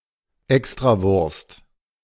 Extrawurst (German: [ˈɛkstʁaˌvʊʁst]